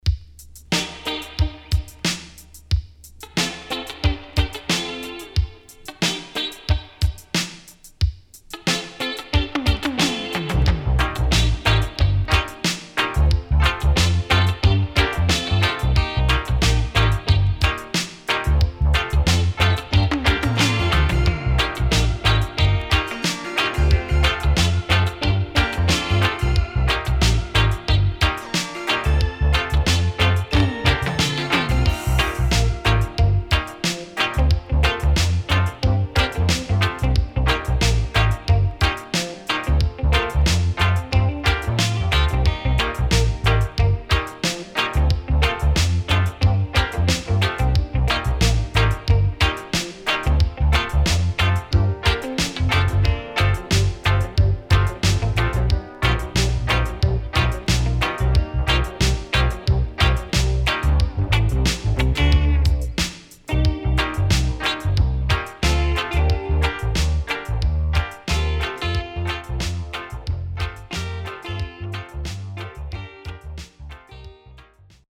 SIDE A:盤質は良好です。